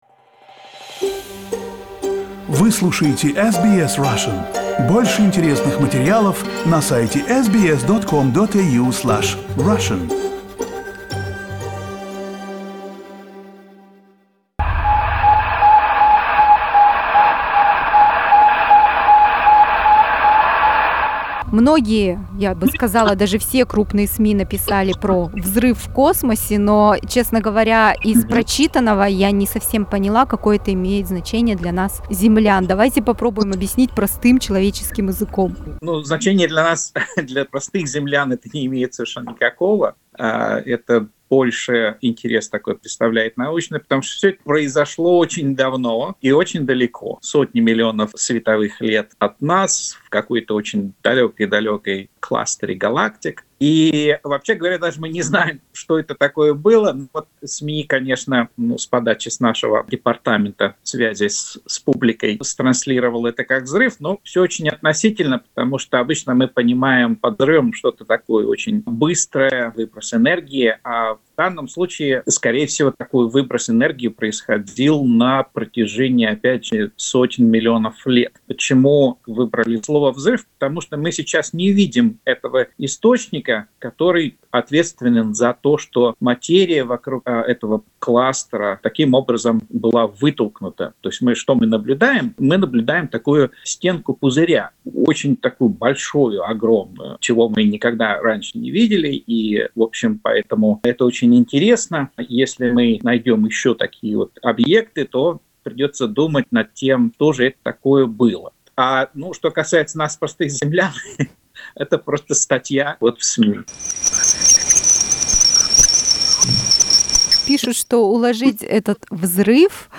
Взрыв в космосе и другие удивительные открытия: беседа с астрономом